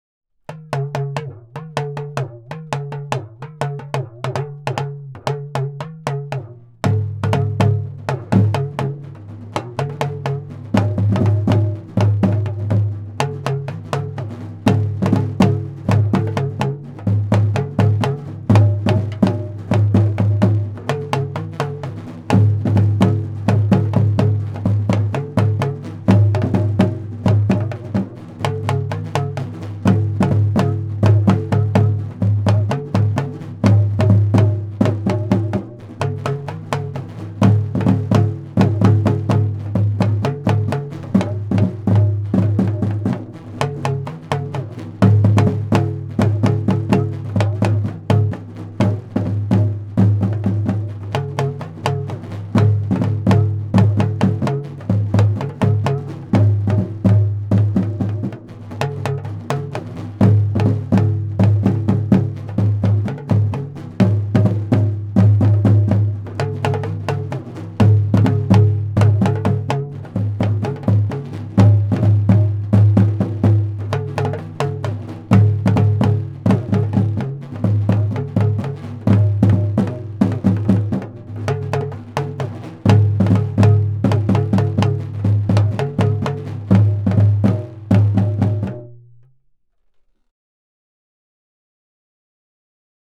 Baamaaya-Dakoli Kutoko drumming ensemble audio, multitrack aggregate
Dagomba drumming African drumming
Talking drums